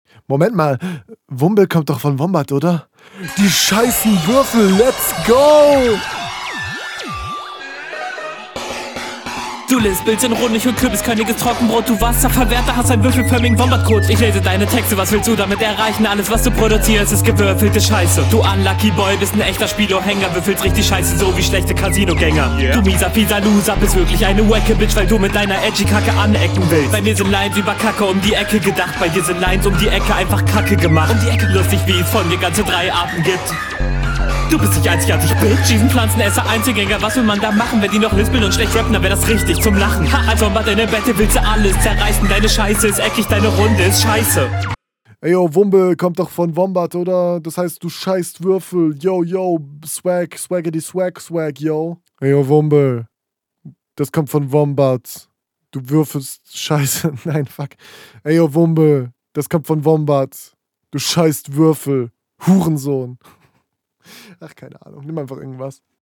Du hast dir einen richtigen Abfuck-Beat ausgesucht und hast leider selbst Probleme, darauf klarzukommen, weshalb …
Finde den Beat seeehr anstrengend, dadurch, dass der auch keinen wirklichen Takt hat, klingt das …